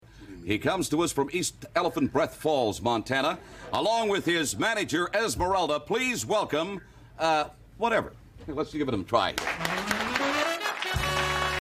It takes Gene about five seconds to stop kidding himself, giving the most apathetic intro you’ll ever hear: